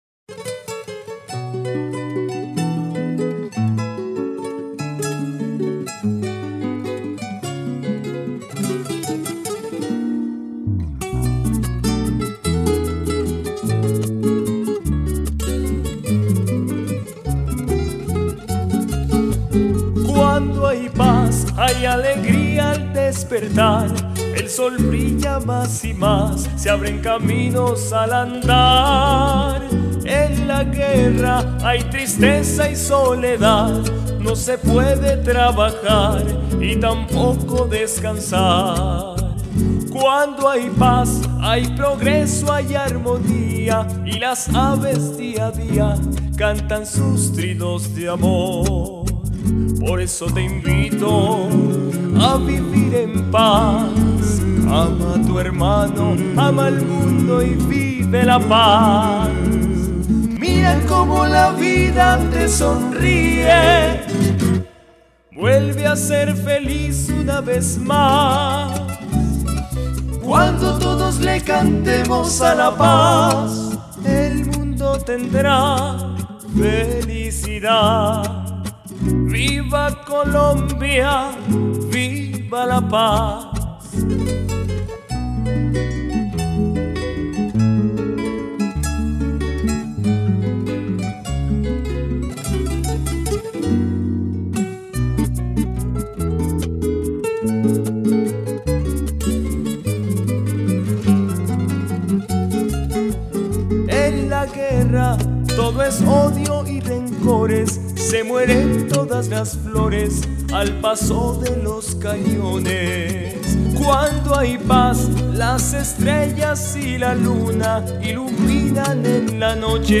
Balada